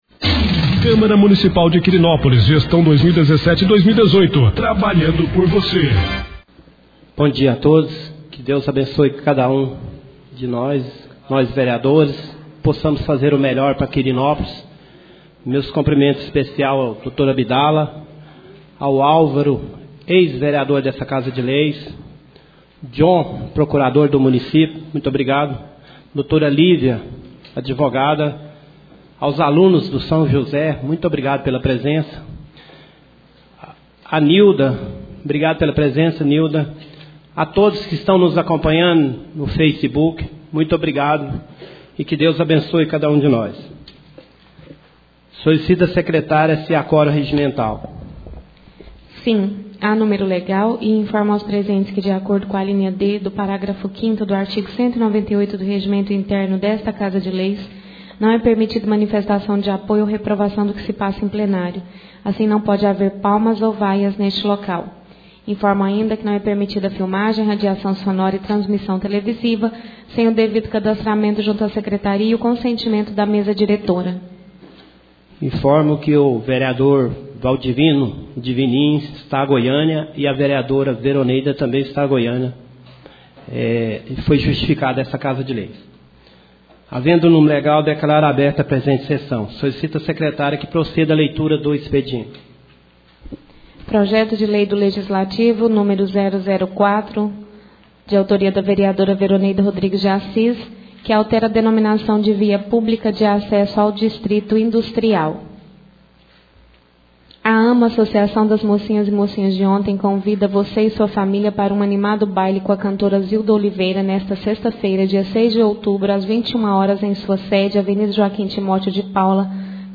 3ª Sessão Ordinária do Mês de Outubro 2017